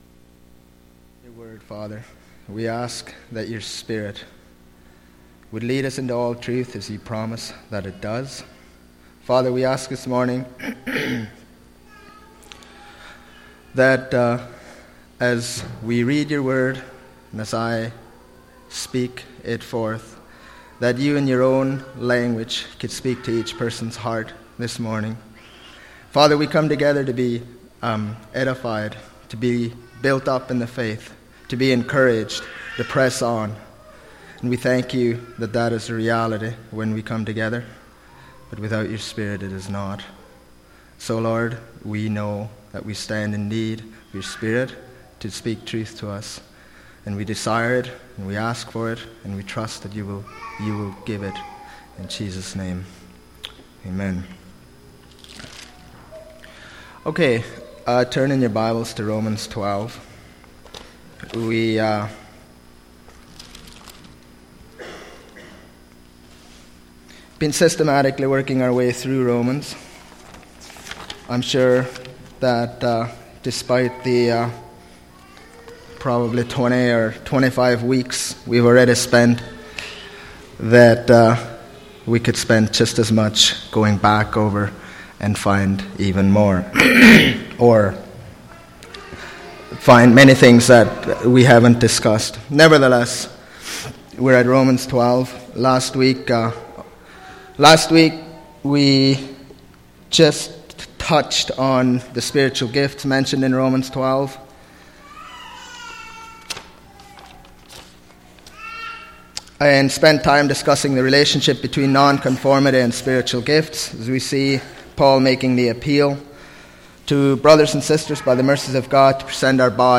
Sunday Morning Bible Study Service Type: Sunday Morning